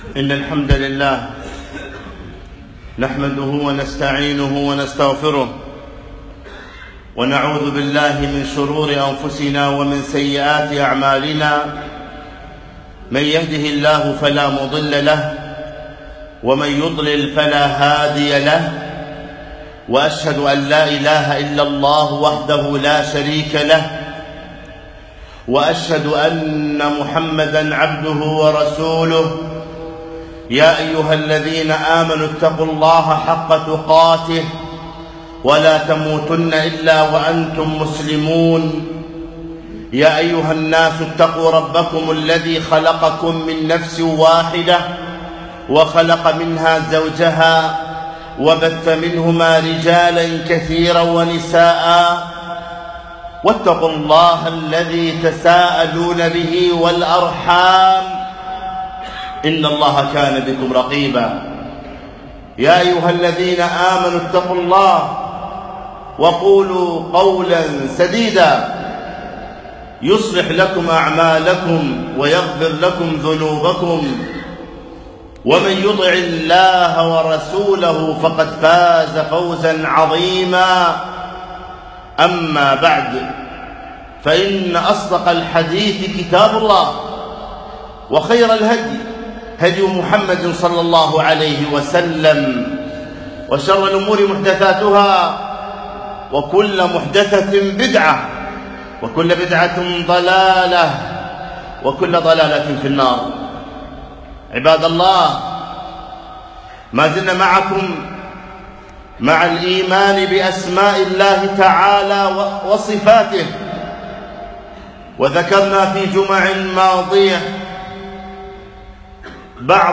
خطبة - تطيب الوجدان بالرحيم الرحمن